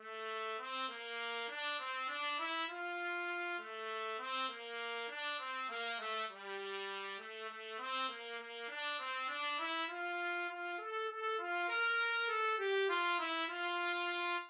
6/8 (View more 6/8 Music)
Allegretto
G4-Bb5
F major (Sounding Pitch) G major (Trumpet in Bb) (View more F major Music for Trumpet )
Beginners Level: Recommended for Beginners
Trumpet  (View more Beginners Trumpet Music)
Traditional (View more Traditional Trumpet Music)